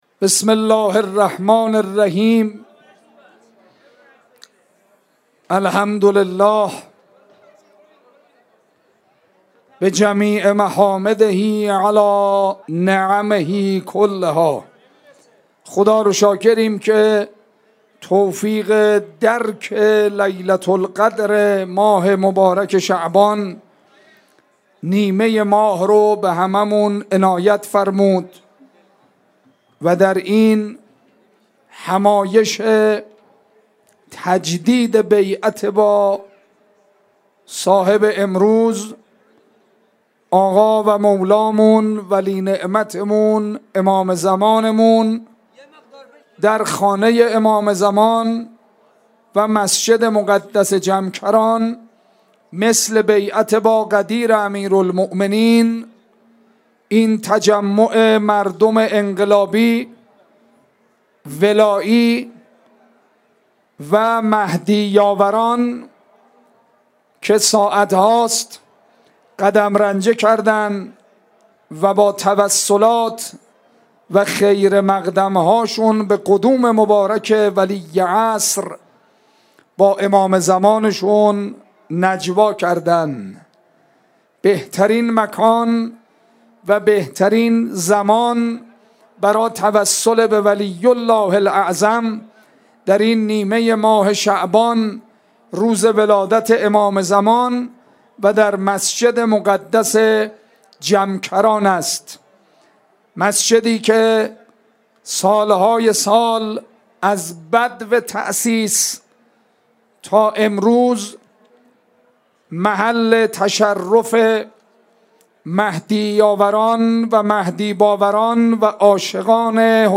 ۱_۲_۱۳۹۸ اجتماع هیات های مذهبی قم